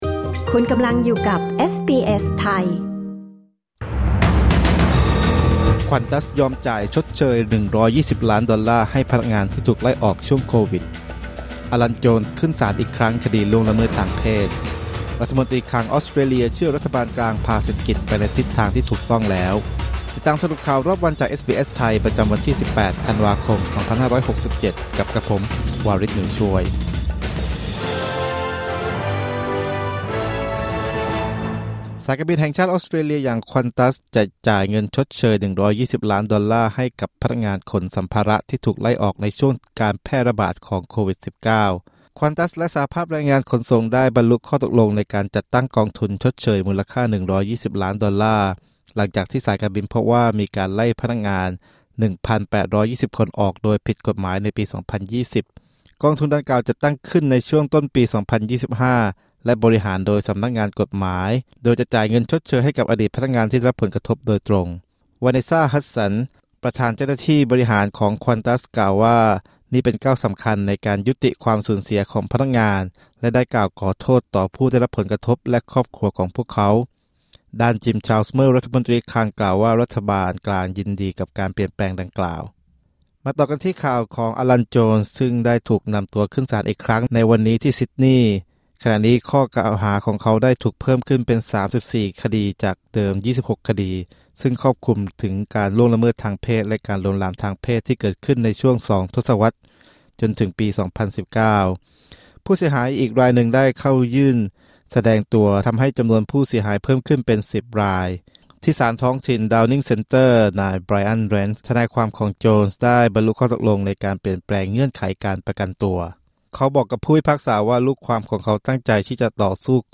สรุปข่าวรอบวัน 18 ธันวาคม 2567